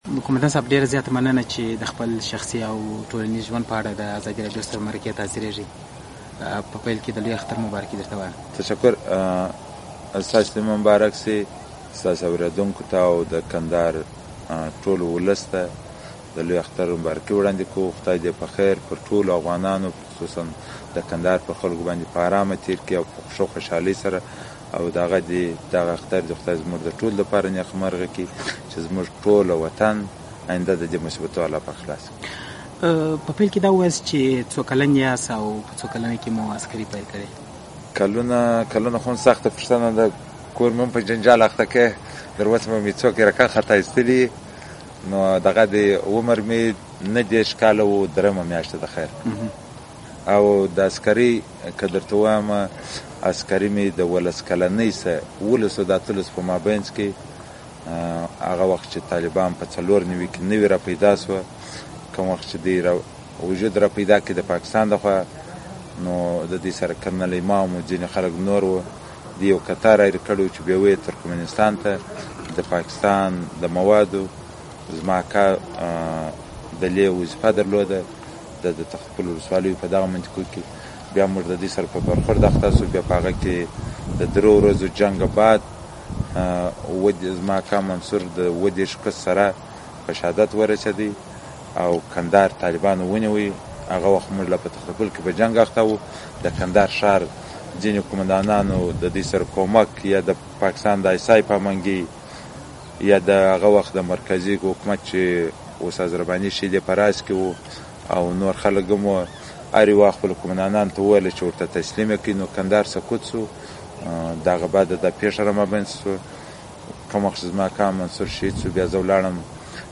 له جنرال عبدالرازق سره مرکه